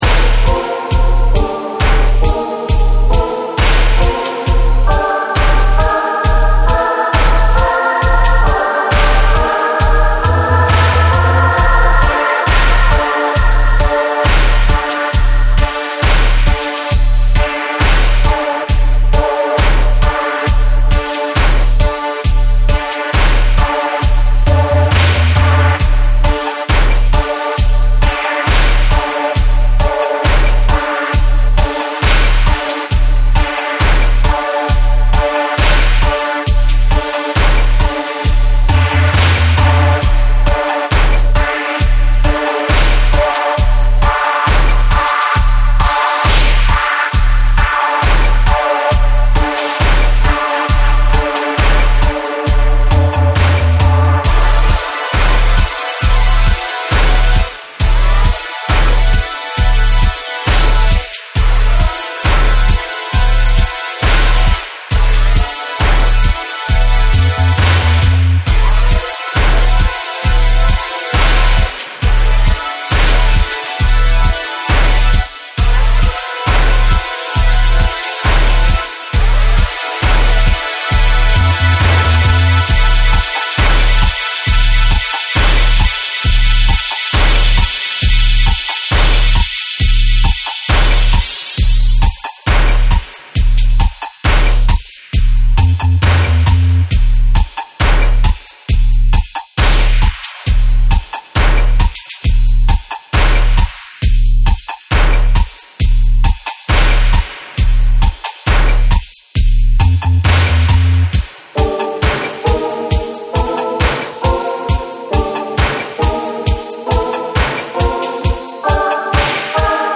アグレッシヴな高速ステッパー
ゴリゴリのベースが渦を巻くエクスペリメンタル・ダブ
JAPANESE / REGGAE & DUB / NEW RELEASE(新譜)